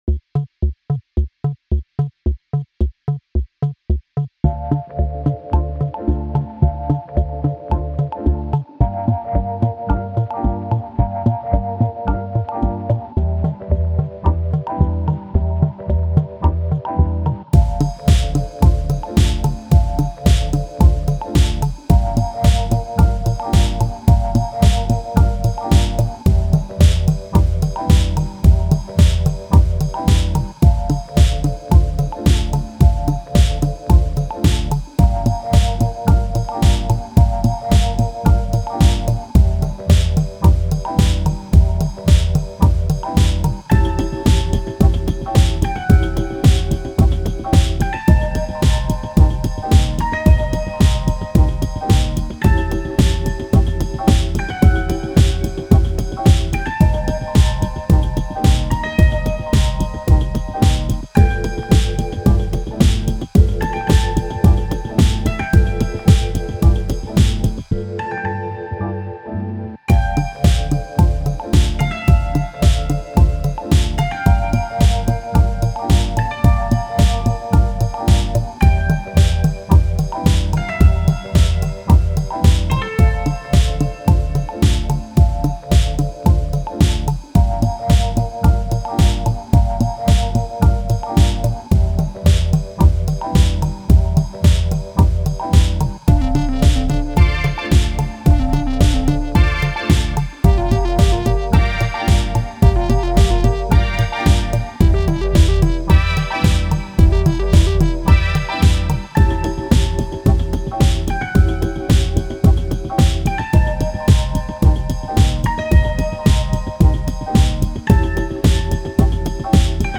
Friendly funk remembered on long buried 8-tracKS